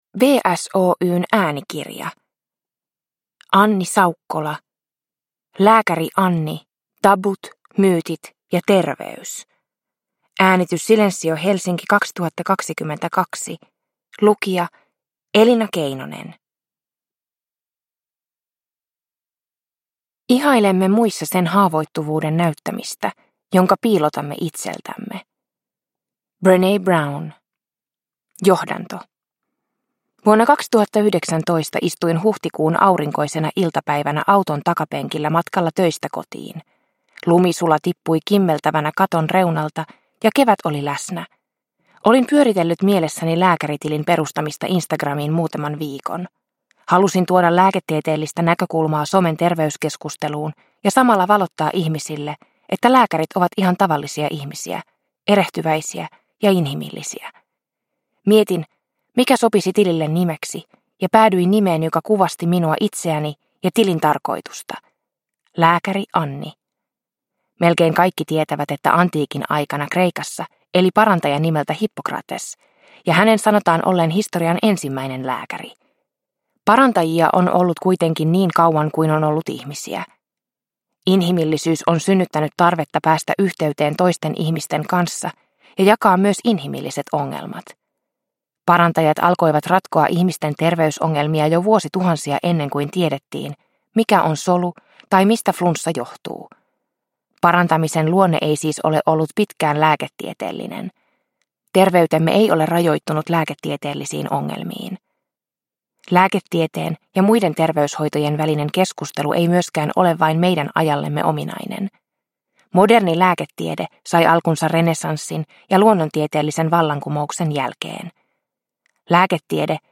Lääkäri Anni - Tabut, myytit ja terveys – Ljudbok – Laddas ner